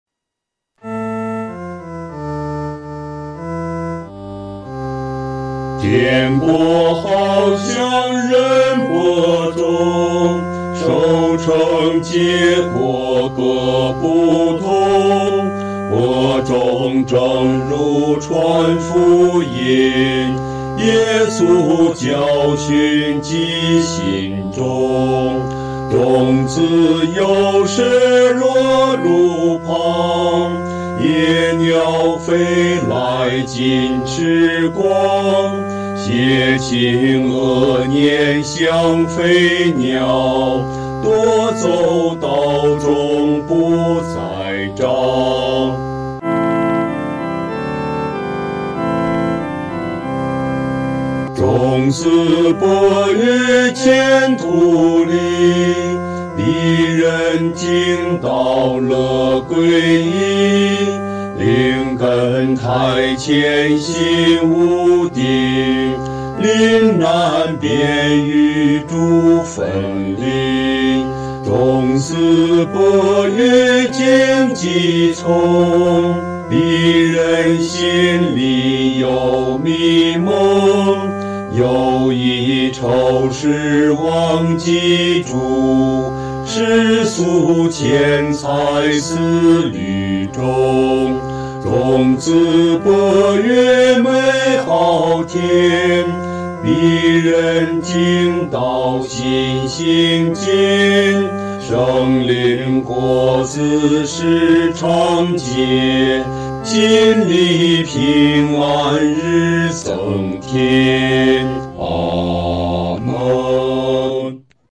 合唱
男低
所用曲谱是在河北省一带于解放前所流行的民歌，曾被配上多种歌词。